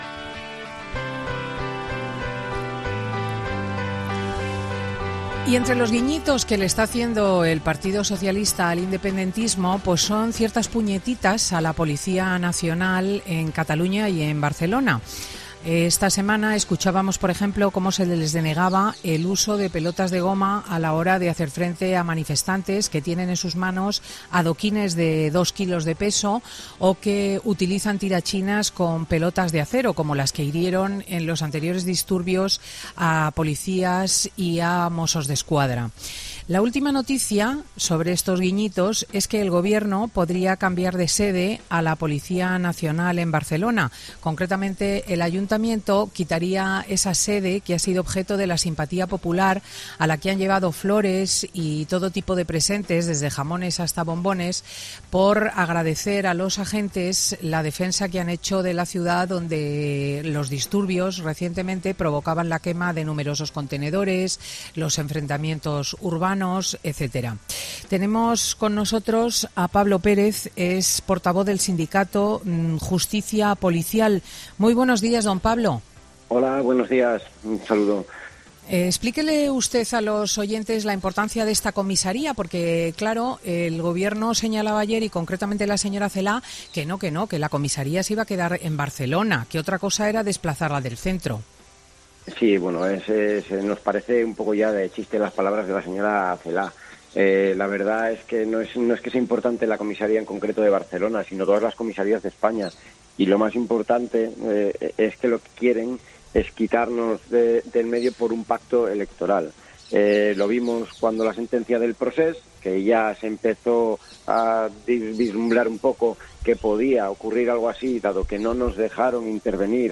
El sindicato mayoritario de la Policía Nacional, Jupol, critica duramente en 'Fin de Semana' el posible traslado de la Jefatura de Policía de Cataluña